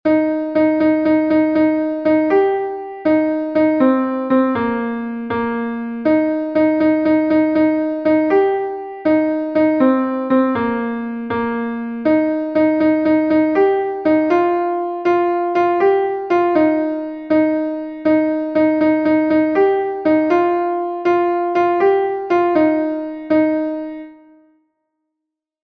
Gavotenn Karaez is a Gavotte from Brittany